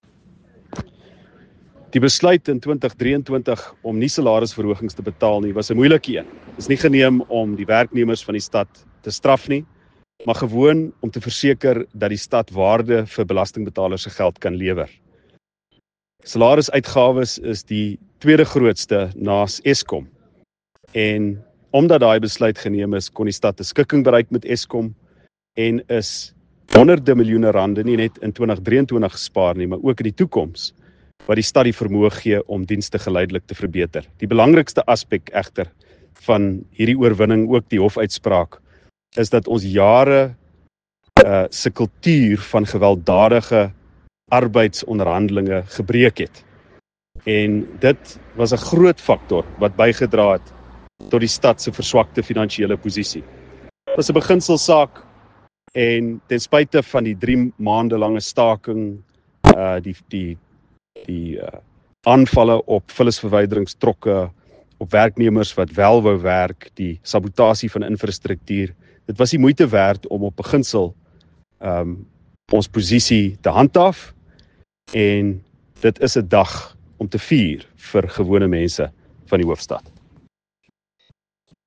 Note to Editors: Please find an English and Afrikaans soundbite by Ald Cilliers Brink here and